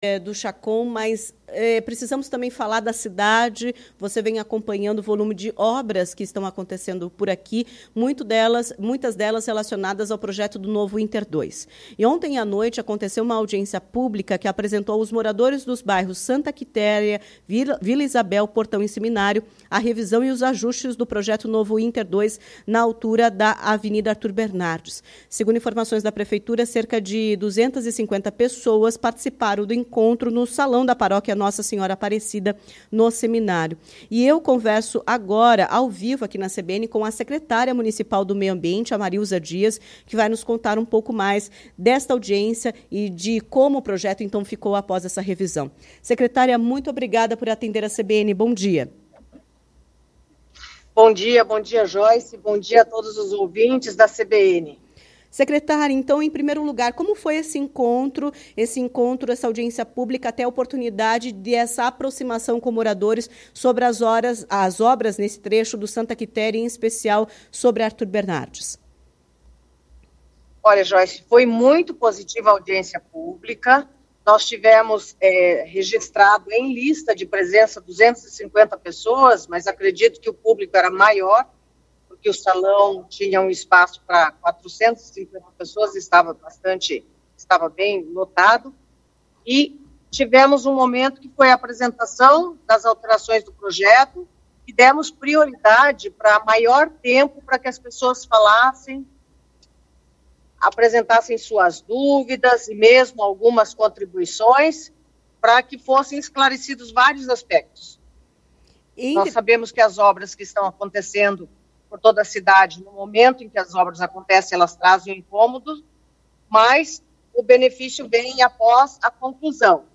Em entrevista à CBN Curitiba nesta quarta (16), a secretária municipal de Meio Ambiente, Marilza do Carmo Oliveira Dias, contou os detalhes desta revisão no projeto, que contempla correção geométrica das vias, o que vai reduzir a quantidade de árvores que serão cortadas para a expansão do número de faixas na avenida. Agora, o corte vai atingir 105 árvores e oito mil serão plantadas na região.